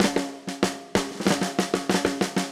AM_MiliSnareA_95-03.wav